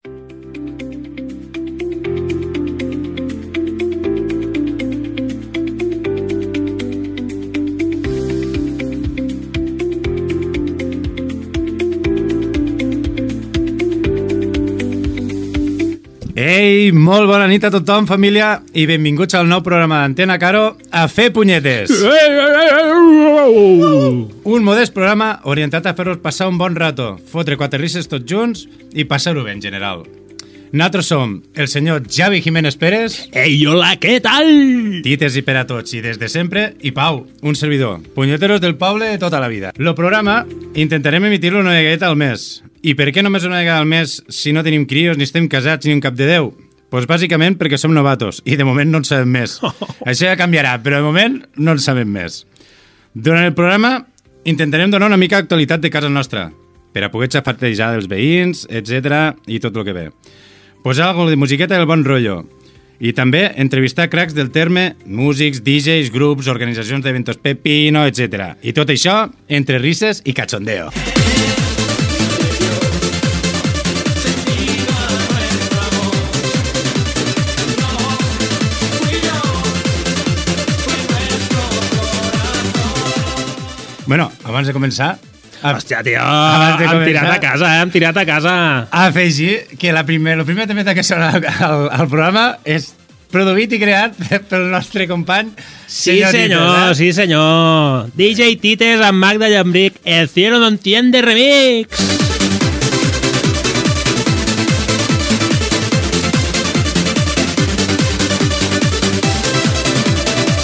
Gènere radiofònic Entreteniment